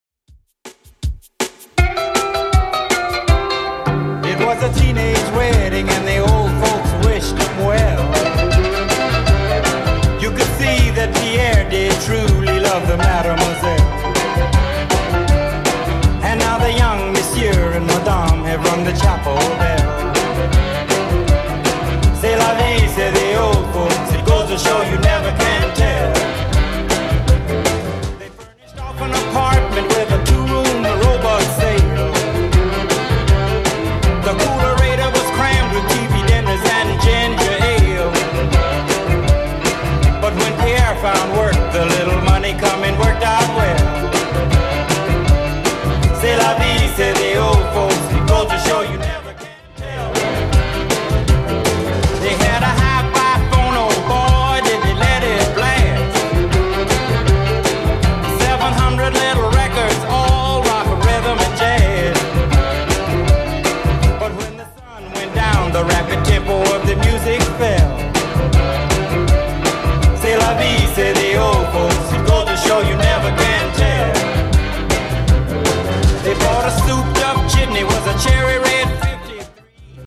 BPM: 160 Time